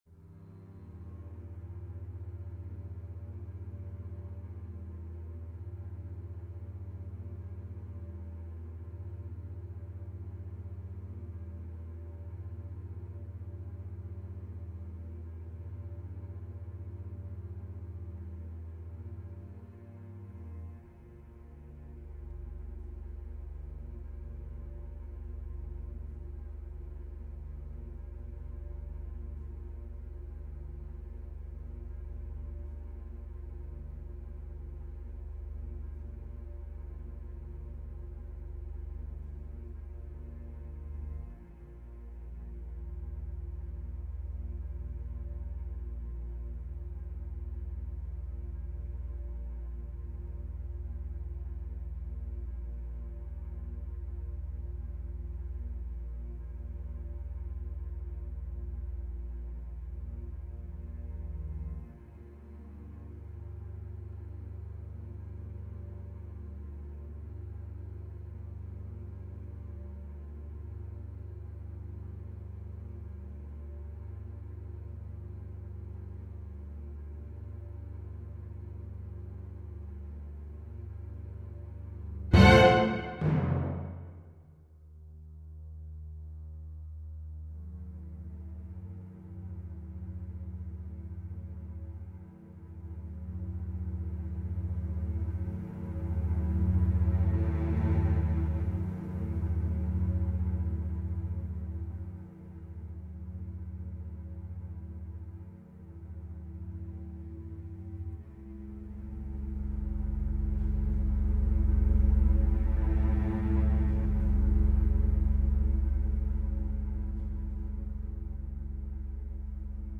Seven Sorrows - Orchestral and Large Ensemble - Young Composers Music Forum